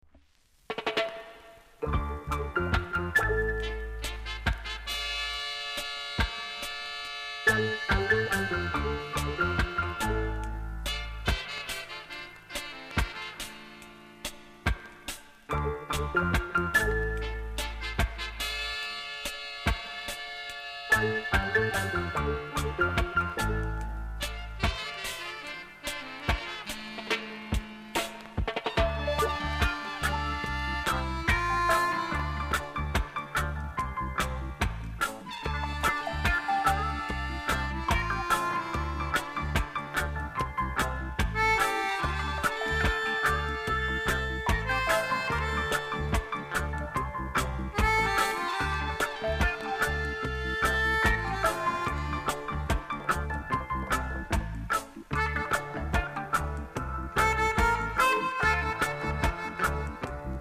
※薄くノイズがあります。ほか小さなチリノイズが少しあります。